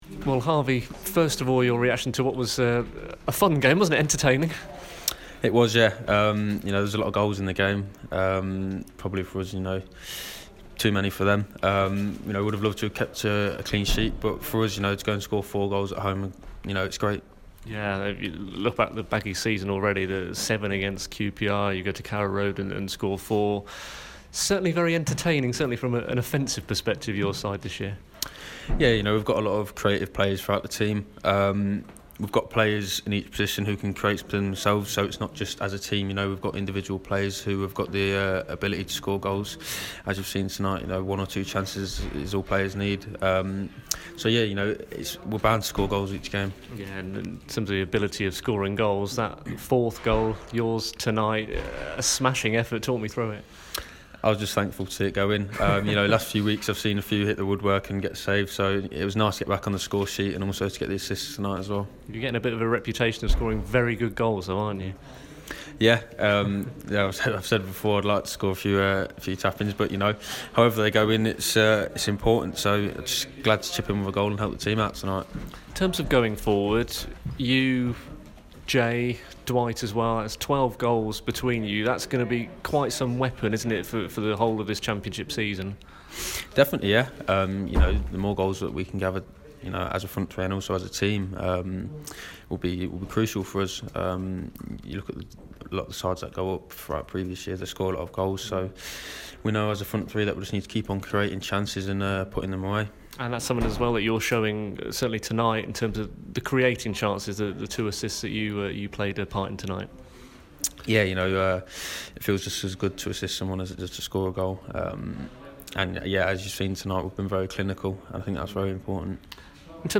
Goalscorer Harvey Barnes talks to BBC WM following West Brom's 4-2 win over Bristol City.